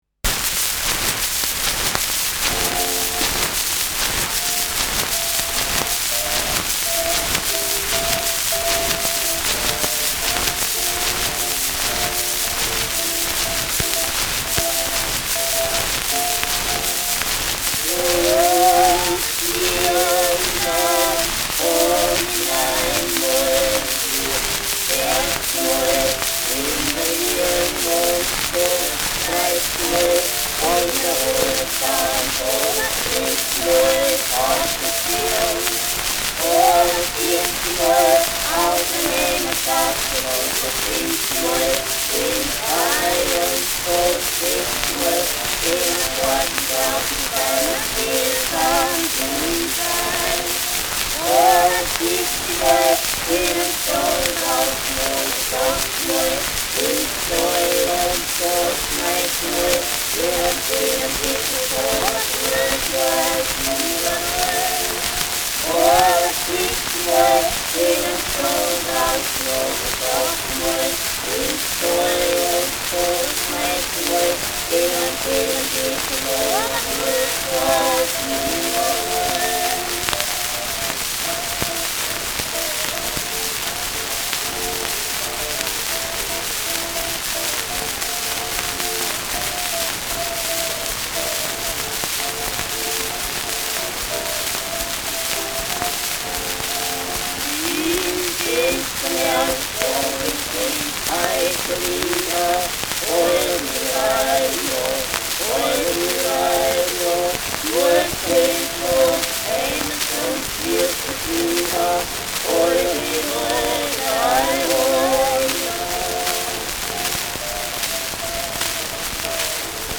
Schellackplatte
sehr starkes bis starkes Rauschen : Knacken : starkes Knistern : abgespielt : leiert : Nadelgeräusch
Teufel-Pühringer-Terzett (Interpretation)